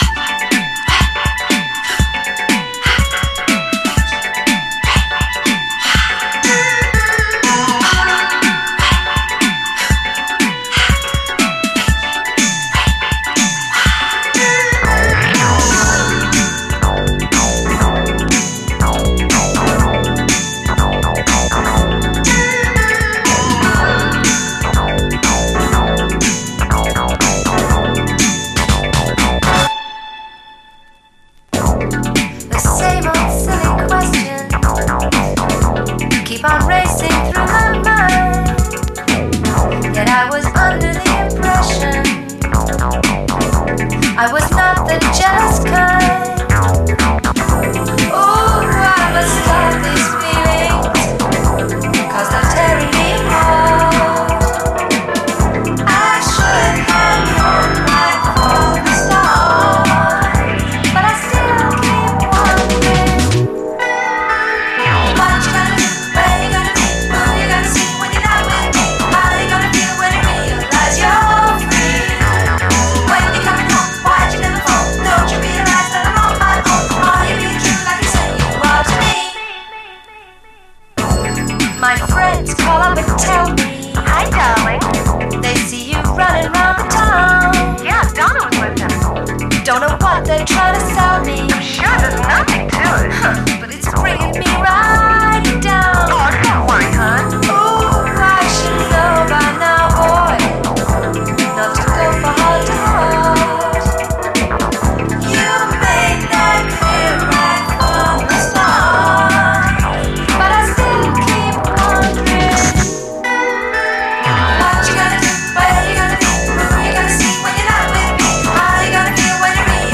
DISCO
シングル・オンリーの最高シンセ・ブギー！